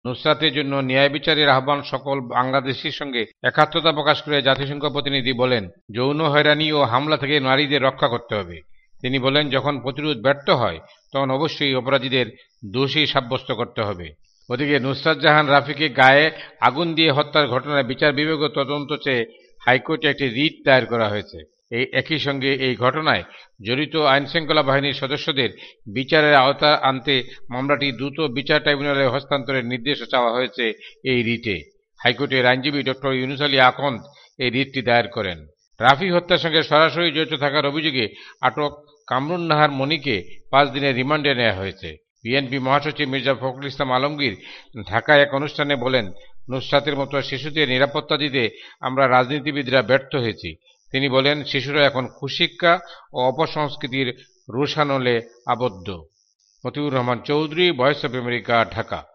ঢাকা থেকে
রিপোর্ট।